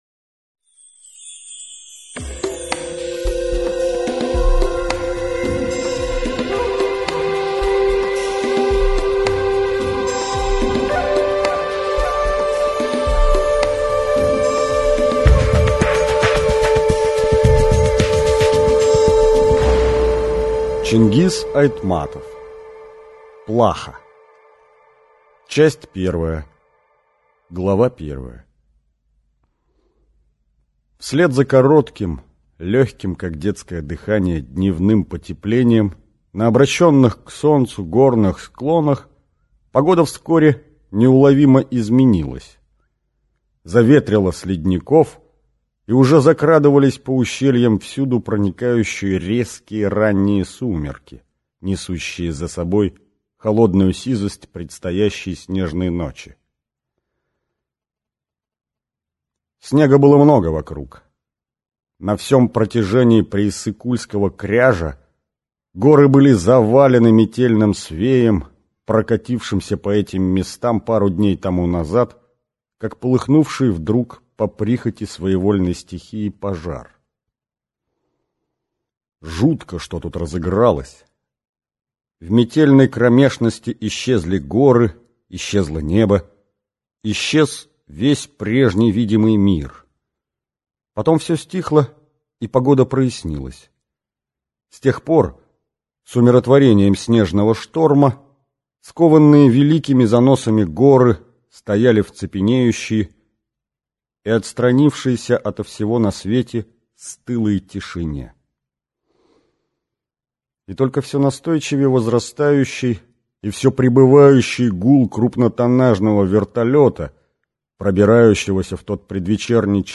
Аудиокнига Плаха - купить, скачать и слушать онлайн | КнигоПоиск